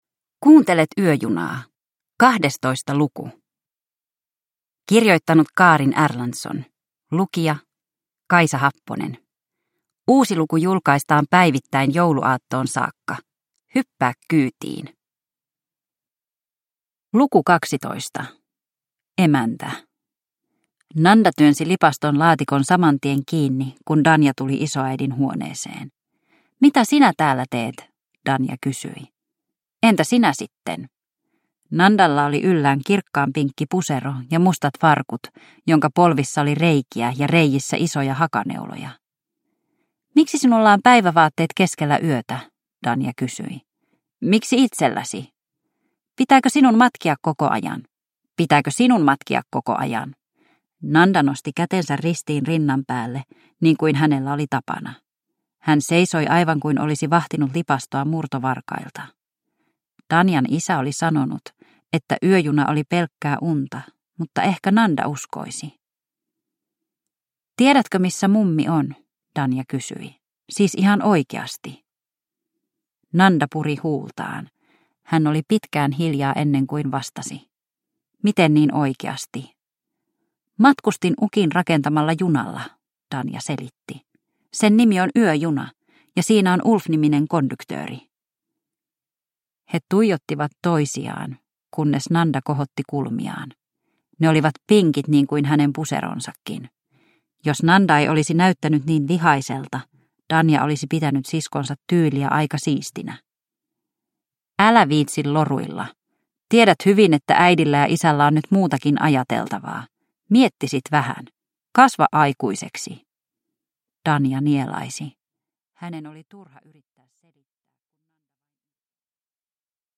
Yöjuna luku 12 – Ljudbok